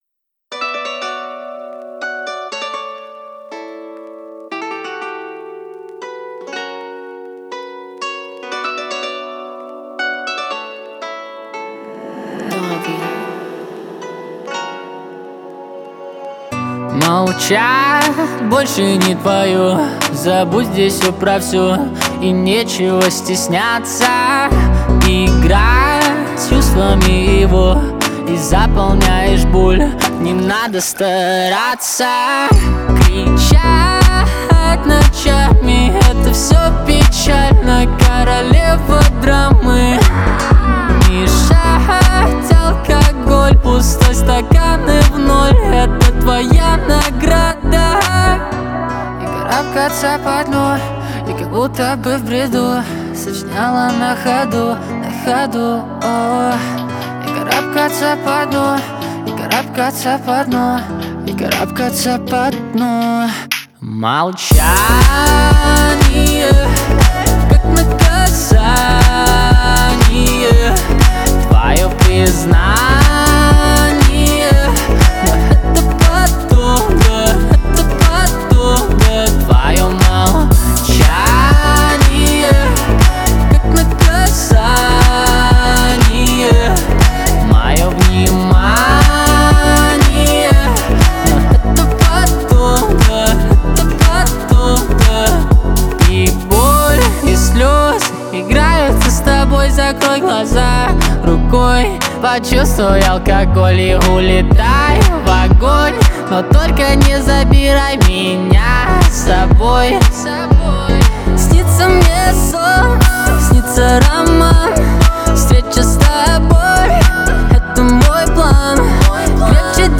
Русская модная музыка
танцевальные песни